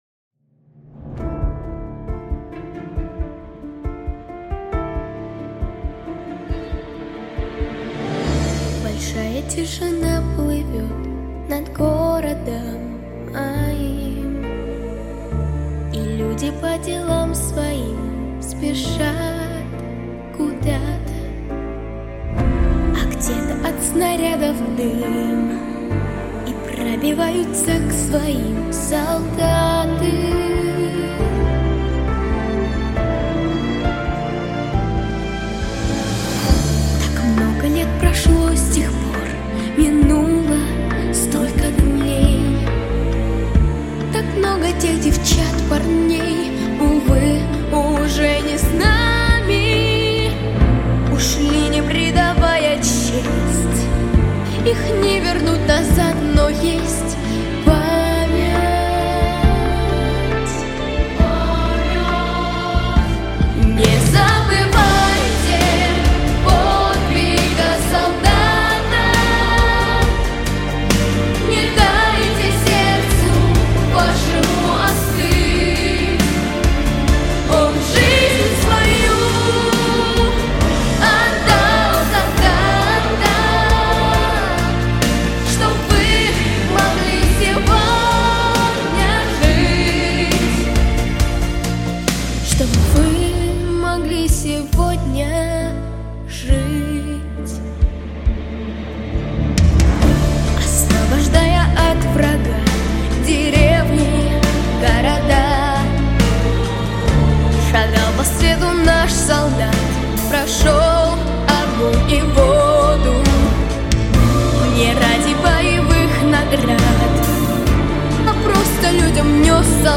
🎶 Детские песни / Песни на праздник / День Победы🕊